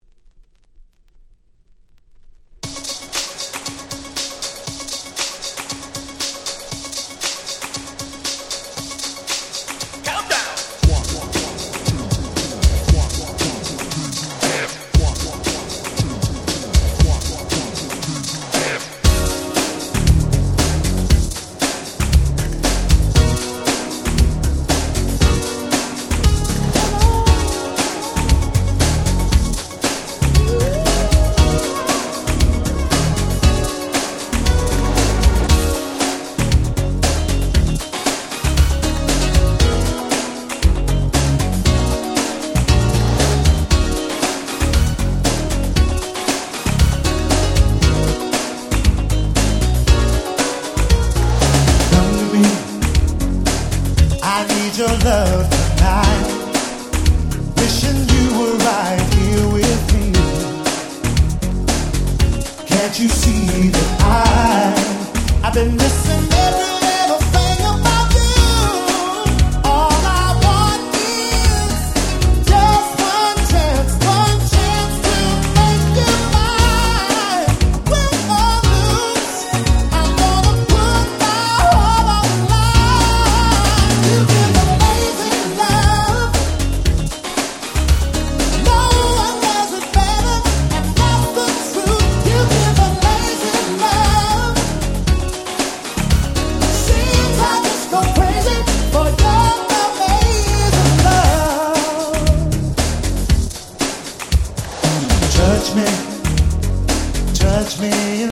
91' Very Nice R&B / ブラコン！！
疾走感のあるBPMに込み上げるVocal、もう最高です！！